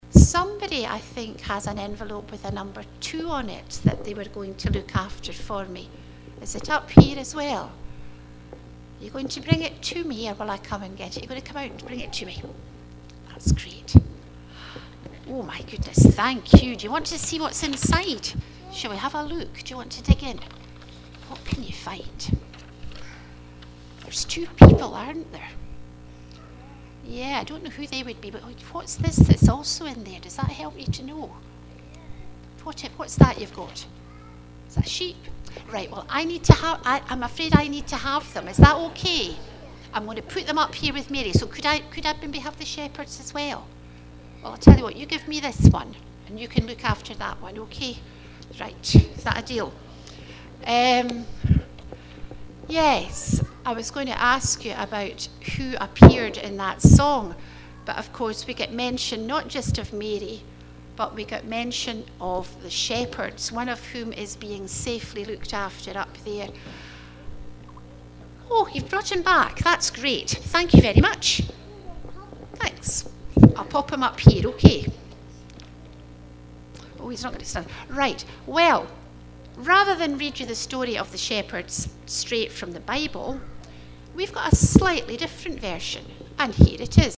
Fourth Advent Afternoon Family Service
Answers such as peace and hope were heard.